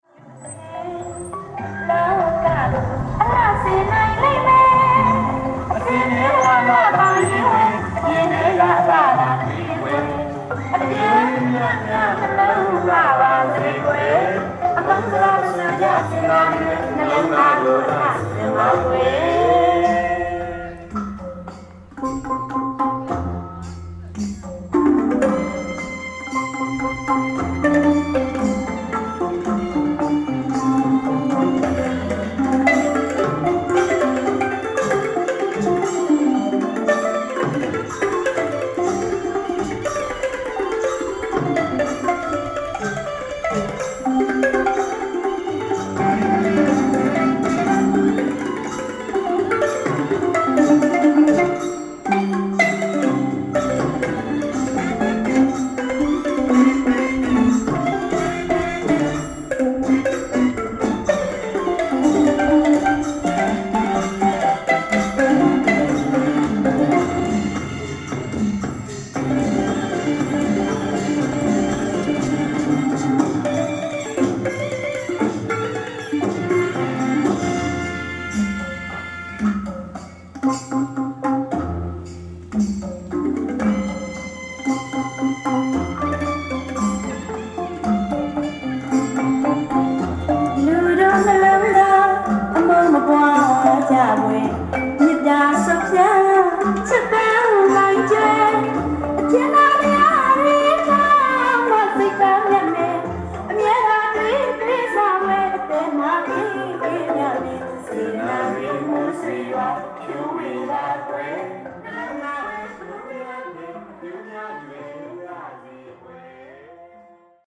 PA sound system recording, Bagan
A PA sound system in amongst the temples in Old Bagan, Myanmar.